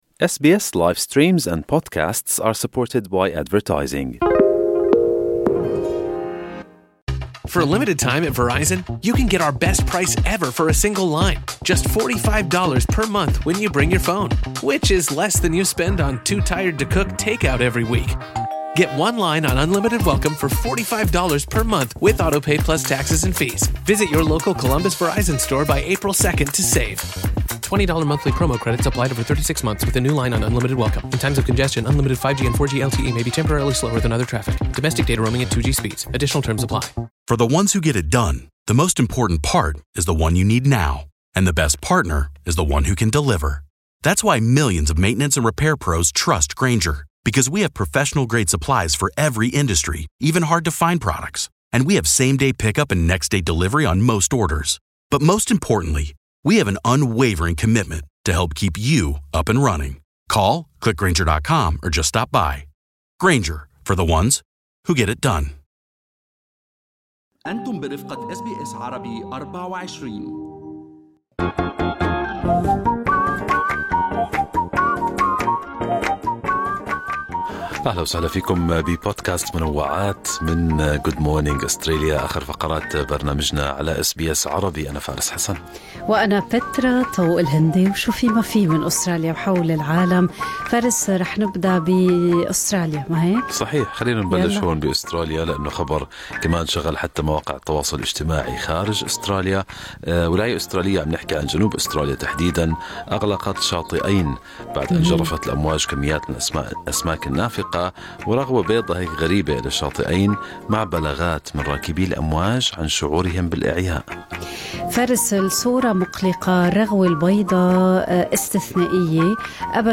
نقدم لكم فقرة المنوعات من برنامج Good Morning Australia التي تحمل إليكم بعض الأخبار والمواضيع الأكثر رواجا على مواقع التواصل الاجتماعي.